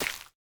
Minecraft Version Minecraft Version 1.21.5 Latest Release | Latest Snapshot 1.21.5 / assets / minecraft / sounds / block / hanging_roots / break3.ogg Compare With Compare With Latest Release | Latest Snapshot
break3.ogg